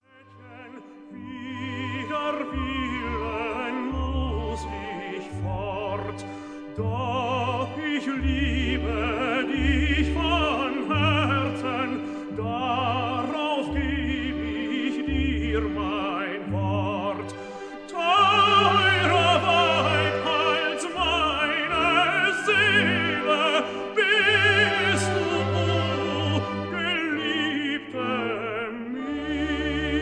Stereo recording made in Berlin